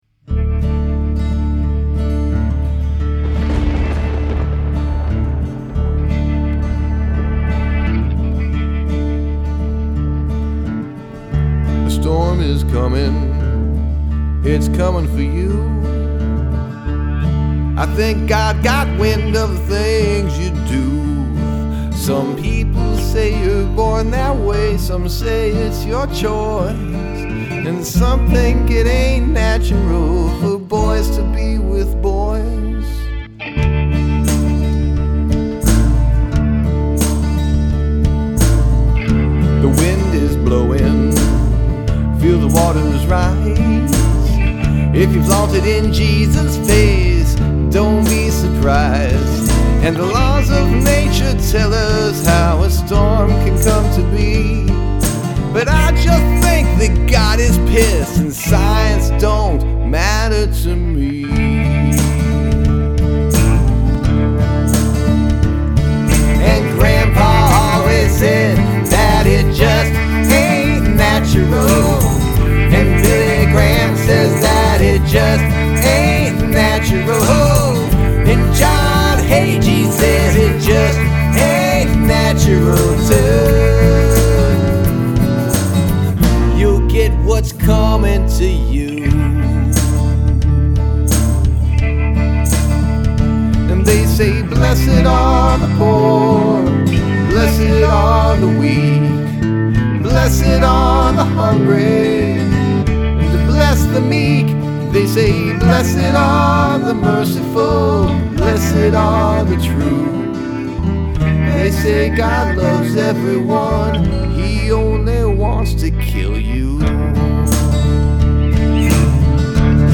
Must include prominent use of backwards recording